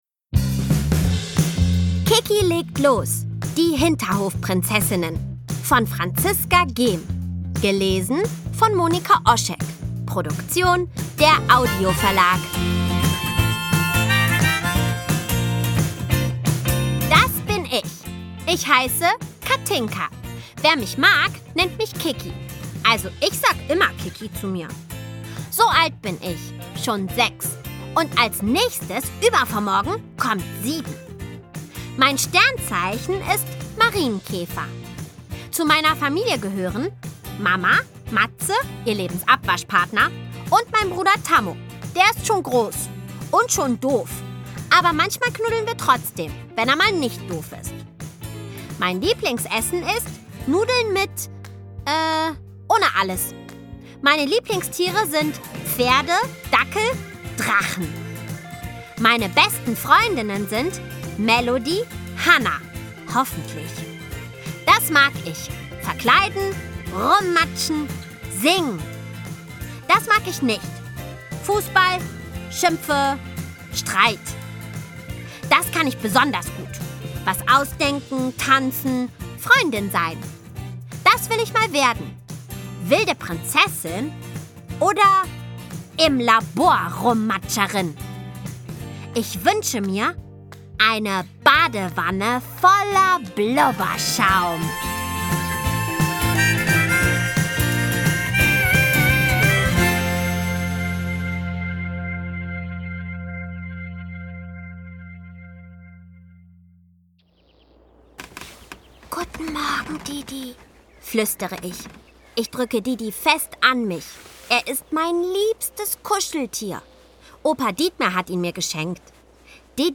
Ungekürzte szenische Lesung mit Musik